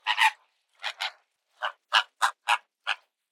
mandrake foundry13data/Data/modules/dynamic-soundscapes/assets/forest_ambient/animals_day
bird_flamingos_05.ogg